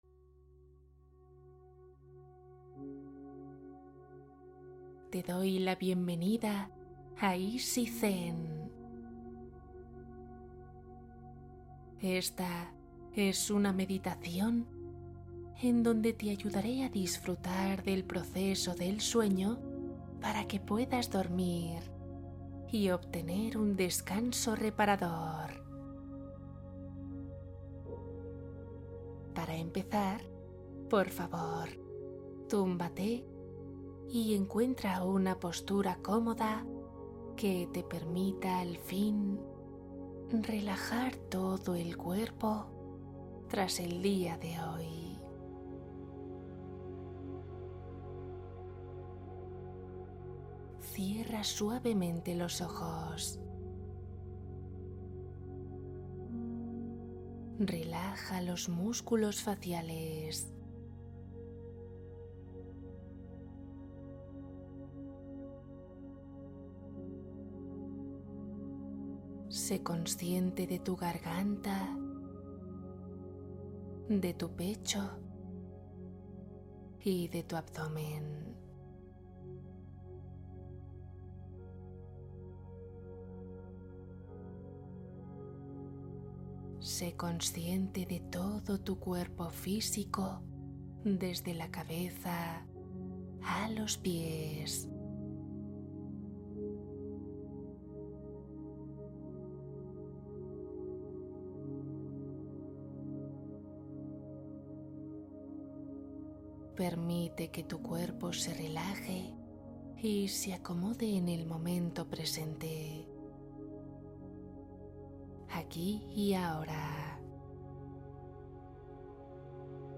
Relajación profunda Meditación para dormir rápido y profundamente